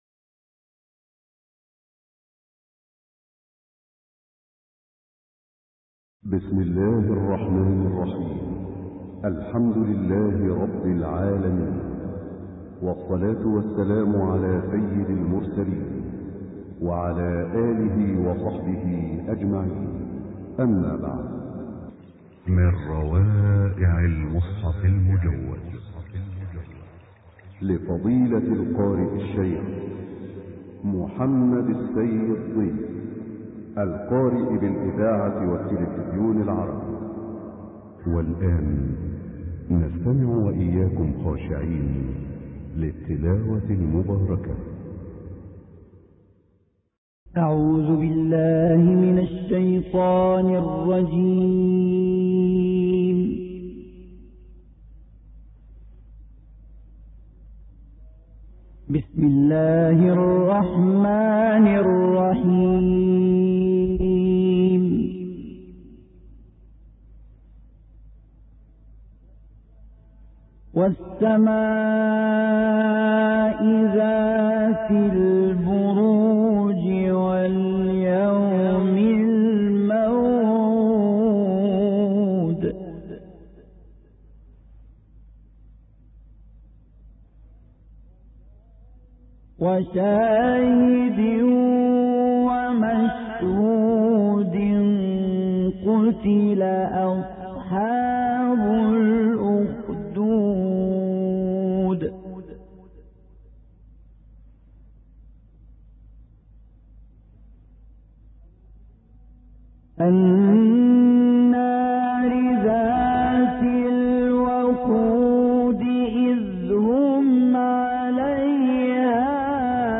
تلاوت معنوی قصار السور